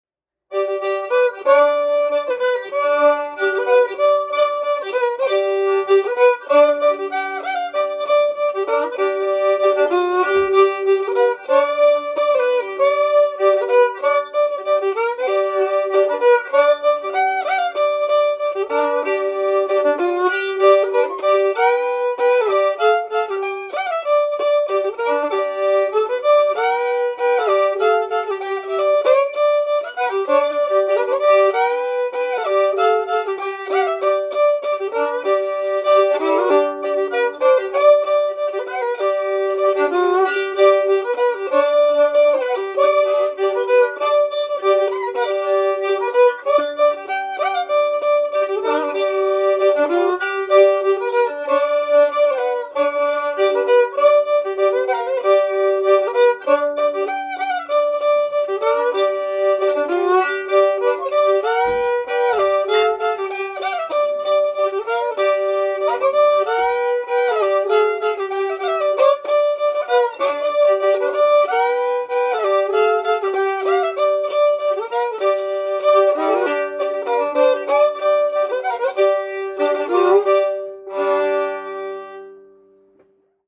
Another member-generated podcast of great fiddle tunes.
Fiddle tuned GDgd.